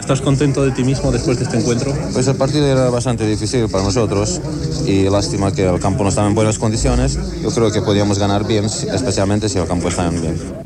Entrevista al futbolista Ladislao Kubala